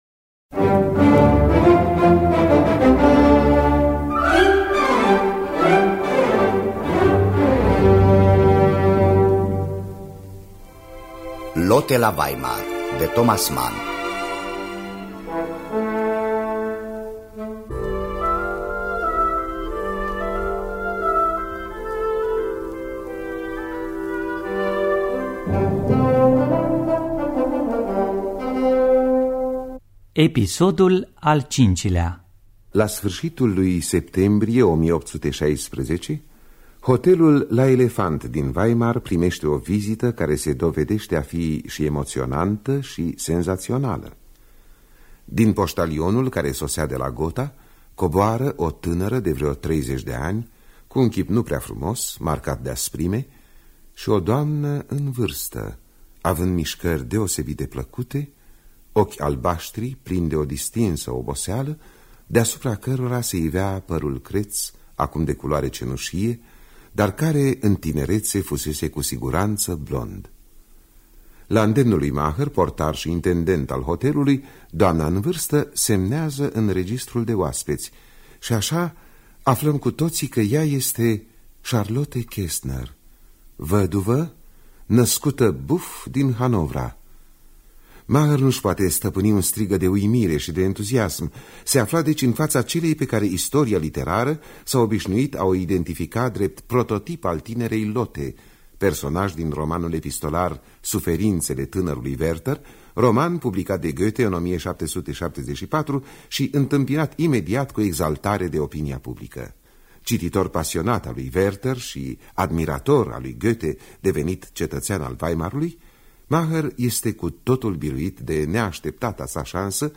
Dramatizarea radiofonică de Antoaneta Tănăsescu.